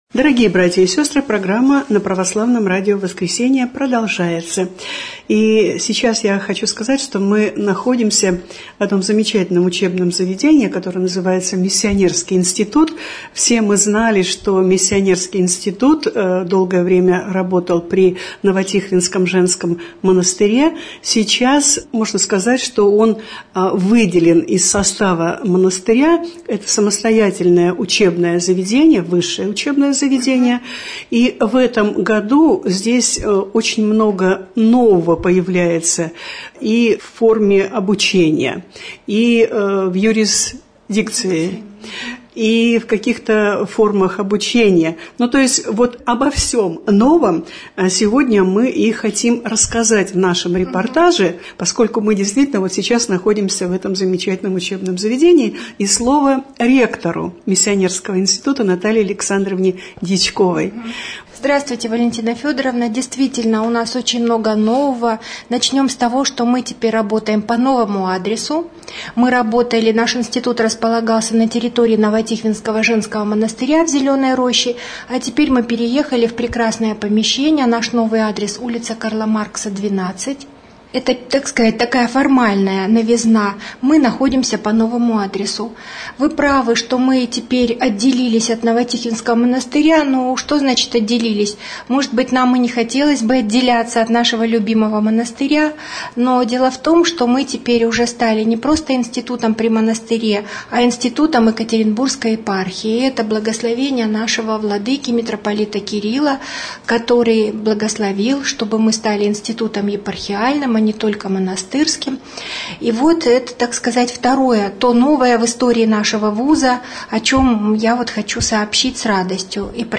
reportazh_iz_missionerskogo_instituta_ch_1.mp3